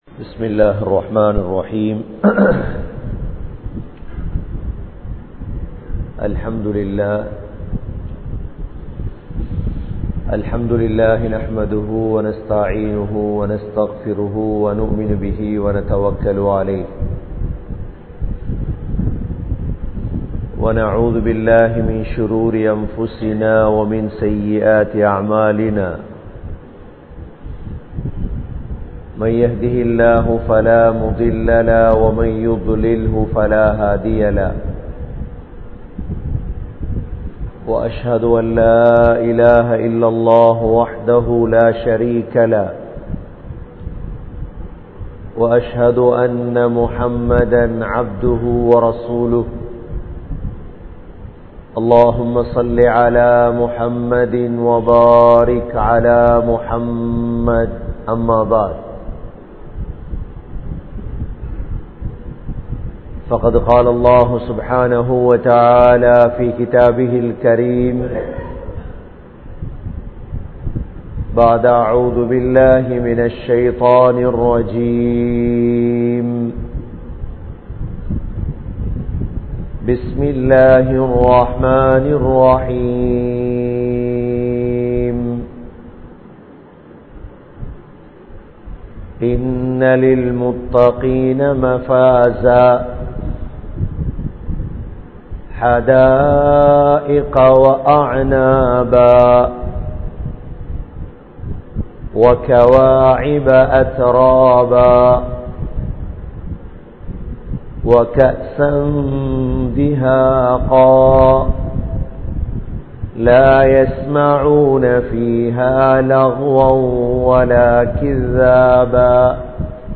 Hajjudaiya Paakkiyam Pettravarhale! (ஹஜ்ஜூடைய பாக்கியம் பெற்றவர்களே!) | Audio Bayans | All Ceylon Muslim Youth Community | Addalaichenai
Sammanthurai,Thakiyyah Masjith